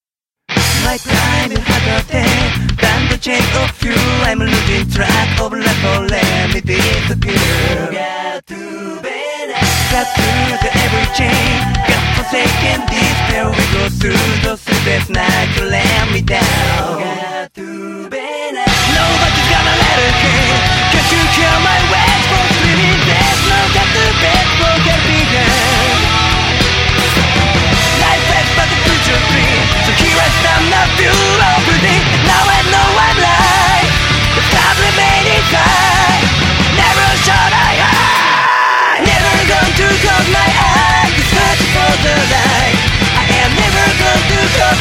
J-Pop